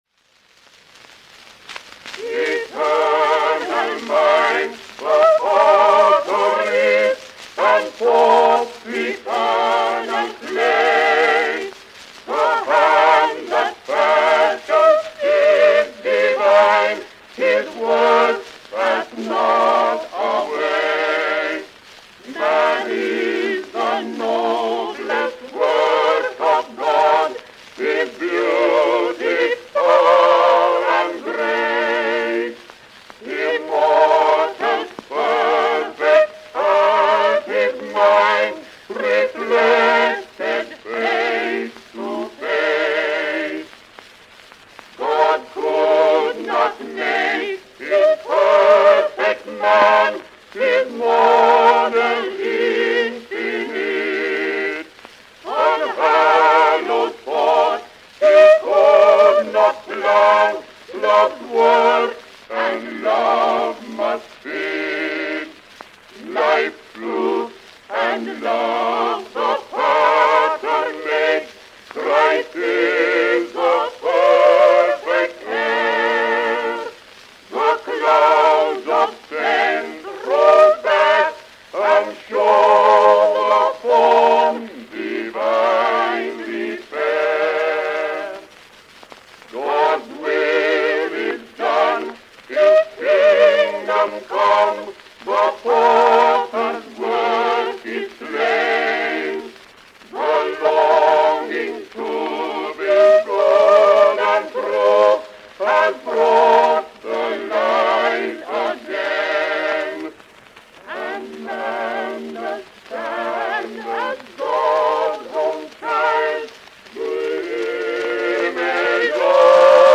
Vocal quartets with orchestra.
Sacred songs.